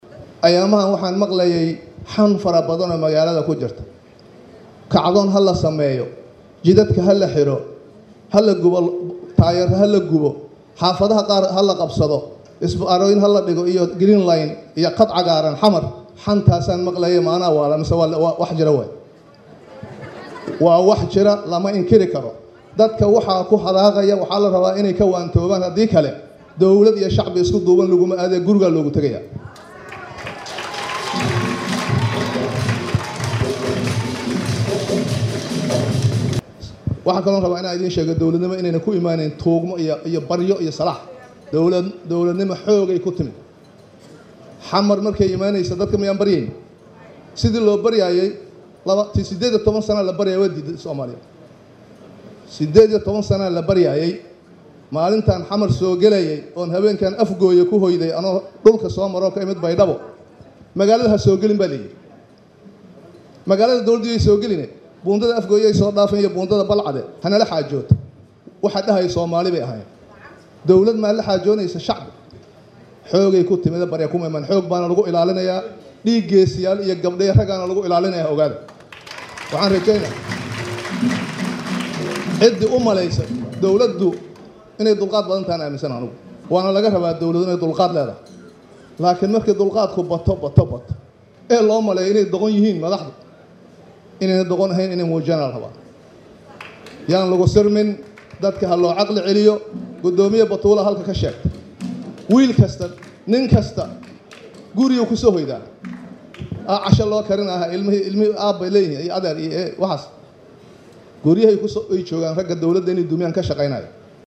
Geedi oo ka hadlay shir maanta ka dhacay Muqdisho ayaa si kulul u dhaliilay dadka mucaaradka ku ah dowladda federaalka ah Soomaaliya.
Dhageyso codka ra’iisul wasaarihii hore ee Soomaaliya